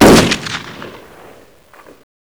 assets/pc/nzp/sounds/weapons/ptrs/shoot.wav at 9ea766f1c2ff1baf68fe27859b7e5b52b329afea